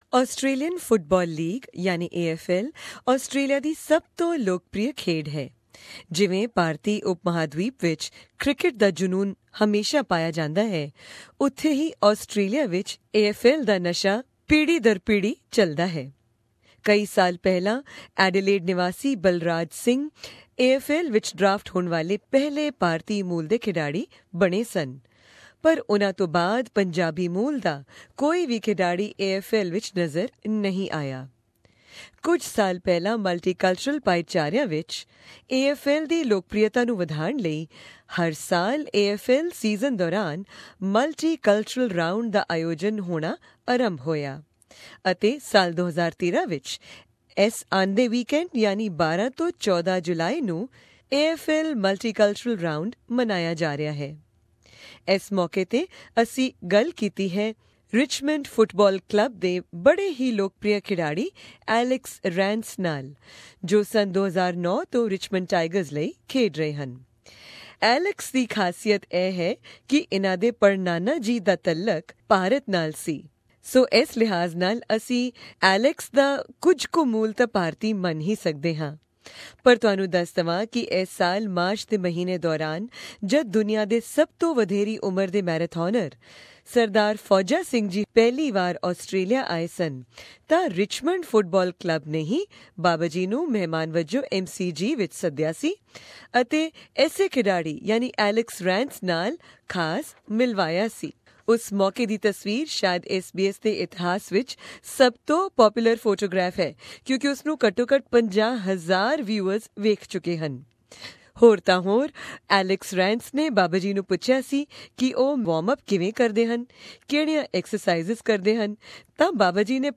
AFL star speaks about multiculturalism, Fauja and his own Indian heritage
And what's more, Alex even has a distant Indian heritage...listen to all that and more in this interview/ feature.